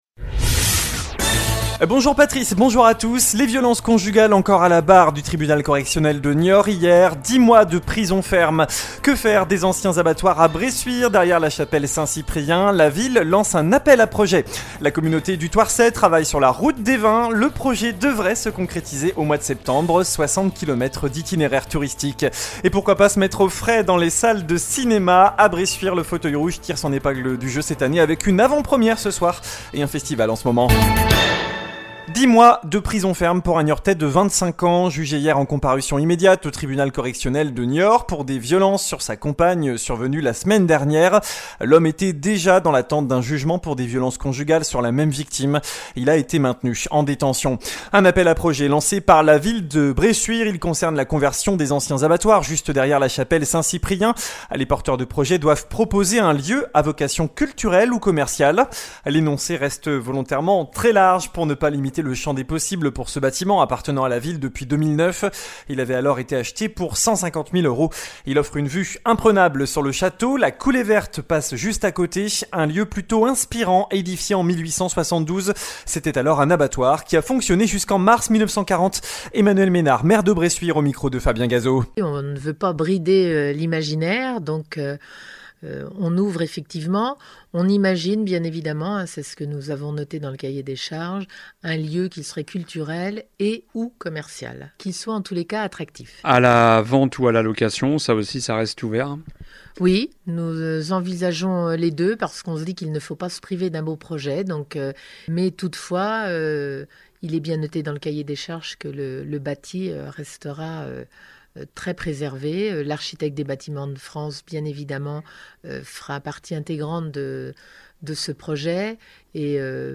JOURNAL DU MARDI 02 AOÛT